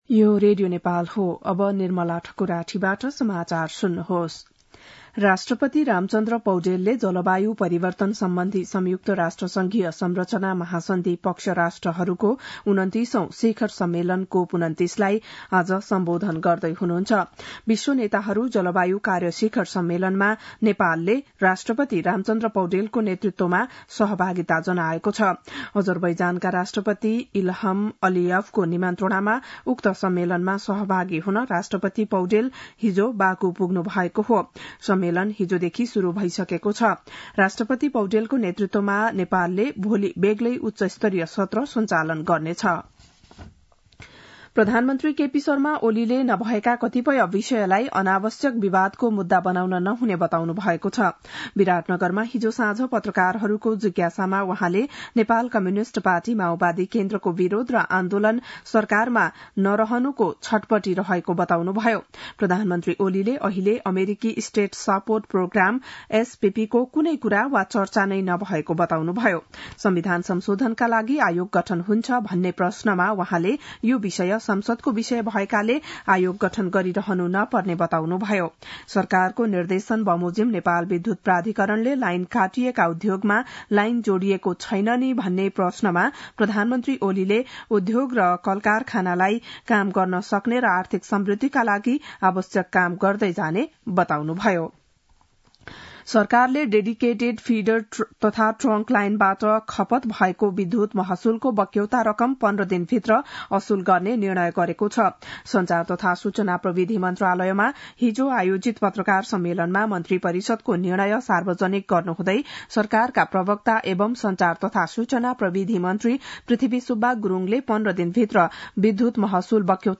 बिहान ११ बजेको नेपाली समाचार : २८ कार्तिक , २०८१
11-am-news-1-2.mp3